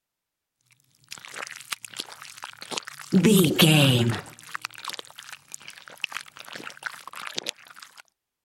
Creature eating flesh juicy chew slow
Sound Effects
scary
disturbing
horror